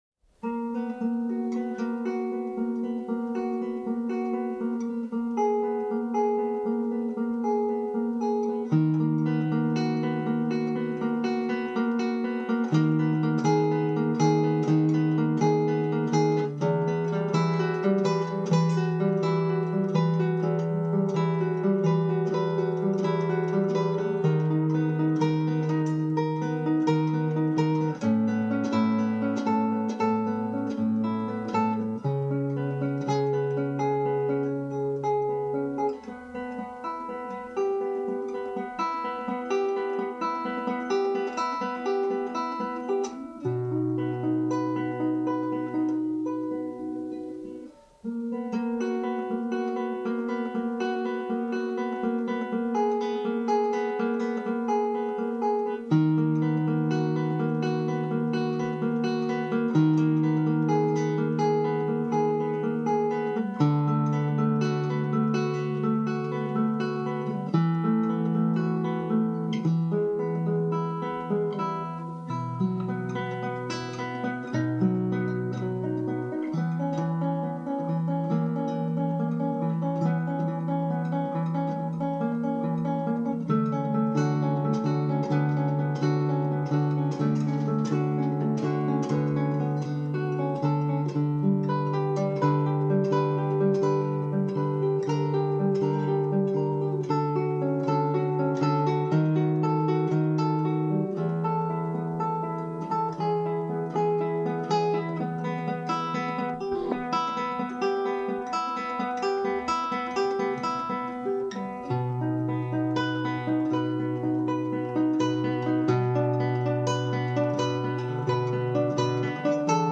I think it’s very cool that I can share a cassette recording of my instrumental “Waterfalls” that was recorded in1980.
Waterfalls Cassette Version 1980
waterfalls-cassette-version.mp3